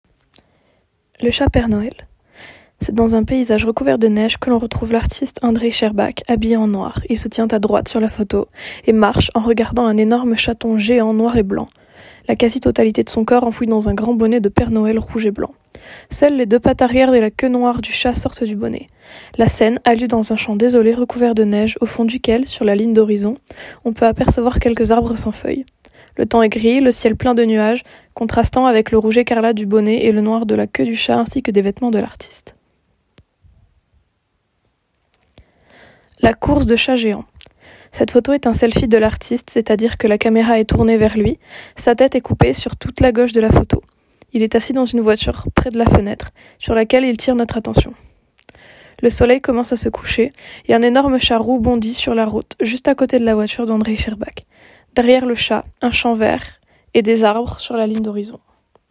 Atelier SUC AD - Visite virtuelle Andrey Shcherbak, groupe 5